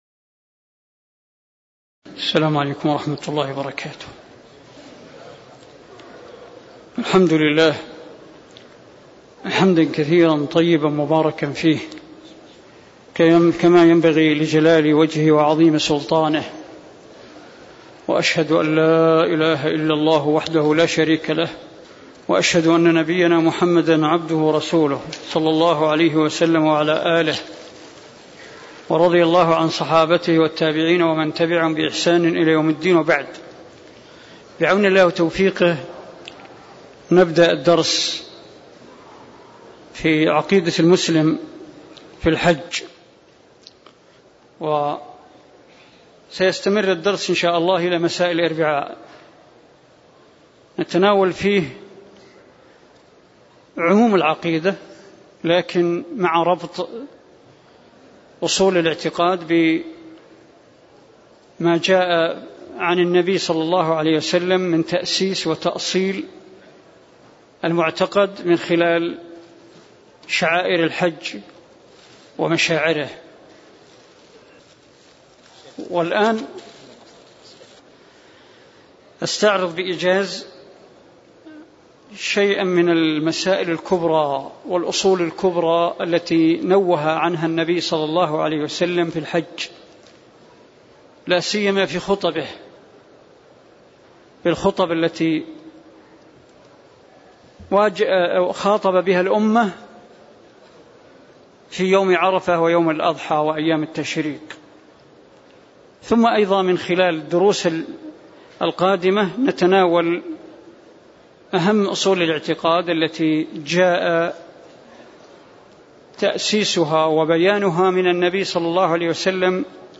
تاريخ النشر ١٤ ذو القعدة ١٤٣٦ هـ المكان: المسجد النبوي الشيخ: ناصر العقل ناصر العقل المقدمة (01)